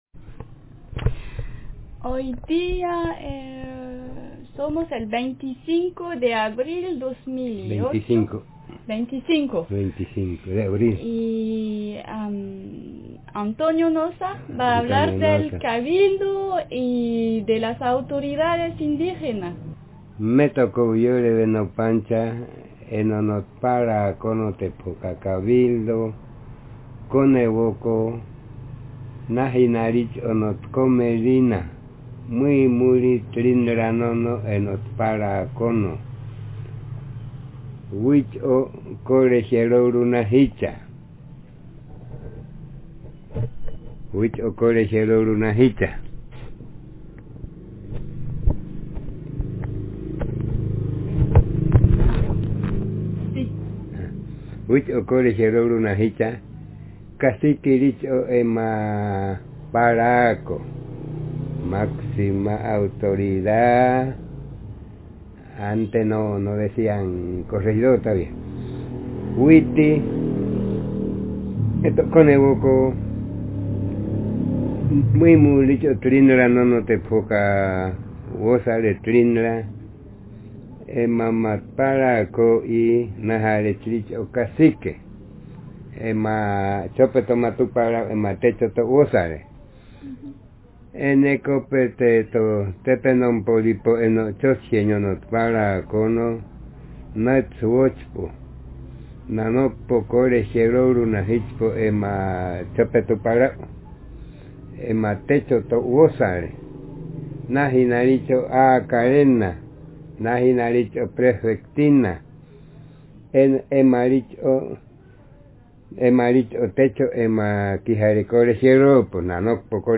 Interlinear glossed text
Speaker sex m Text genre traditional narrative